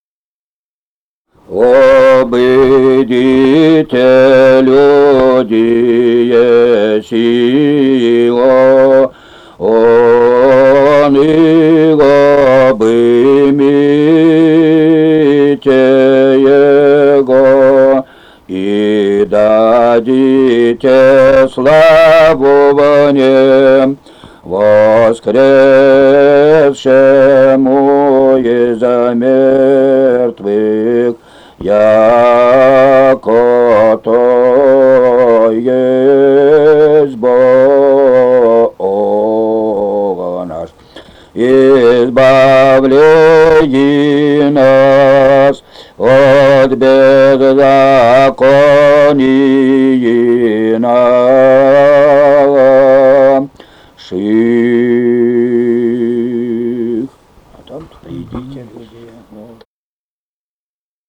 Музыкальный фольклор Климовского района 018. «Обыдите, людие, Сион» Глас 1.
Записали участники экспедиции